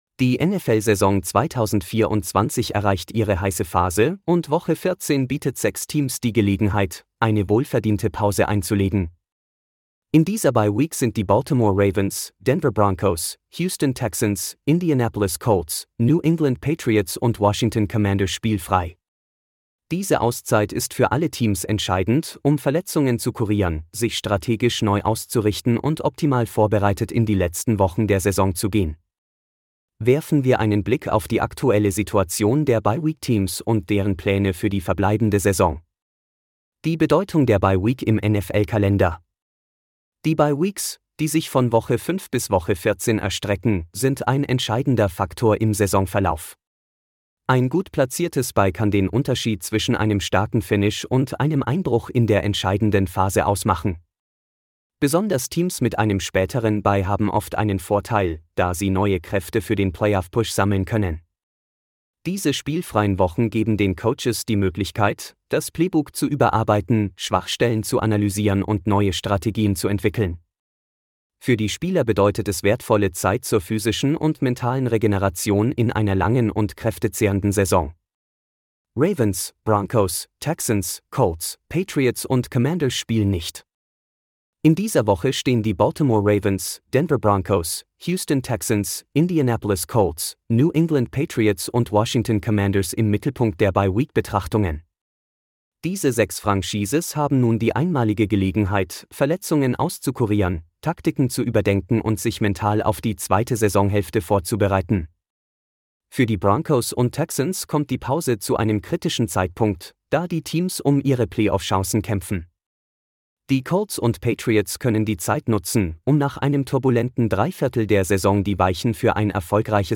Diese Audioversion des Artikels wurde künstlich erzeugt und wird stetig weiterentwickelt.